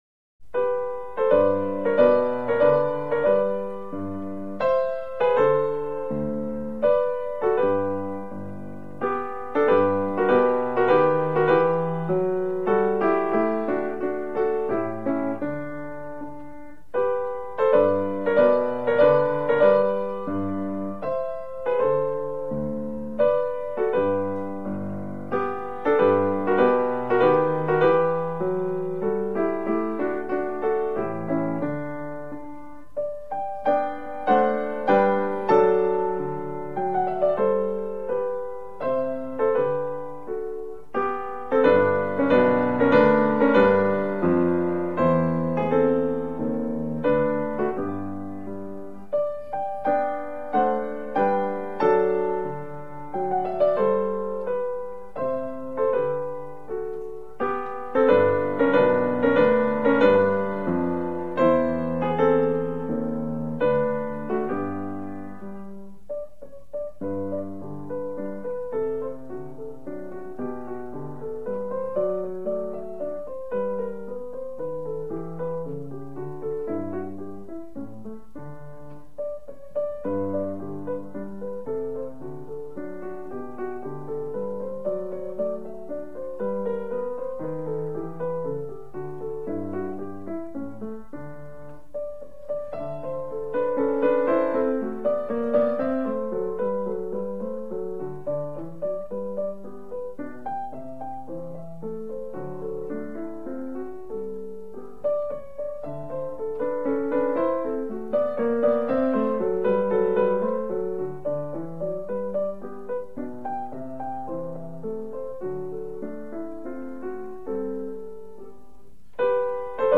0158-钢琴名曲G大调小步舞曲.mp3